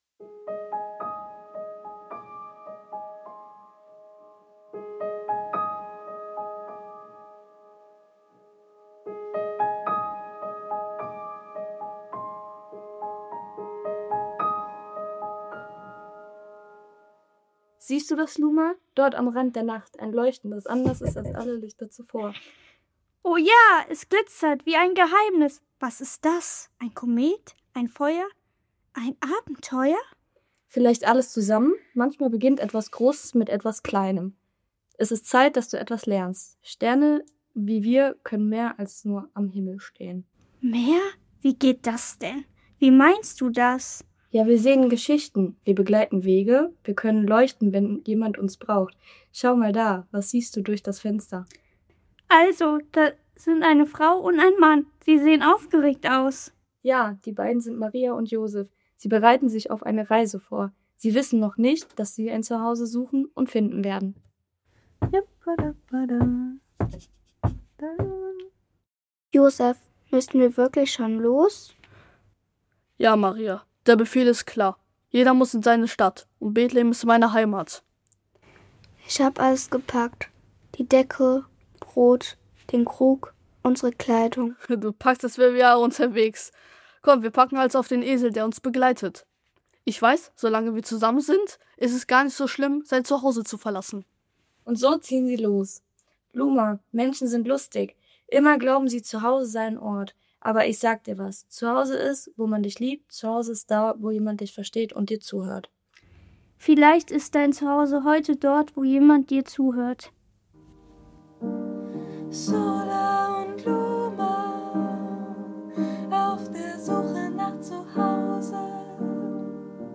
In der ersten Folge unseres Adventskalender-Hörspiels lernen wir die Sterne SOLA und LUMA kennen. Sie beobachten von hoch oben, wie Maria und Josef sich auf eine aufregende Reise nach Bethlehem vorbereiten. Während die beiden Menschen ihr Zuhause verlassen, erzählen die Sterne, dass Zuhause nicht immer ein Ort ist, sondern da, wo man geliebt wird und verstanden wird.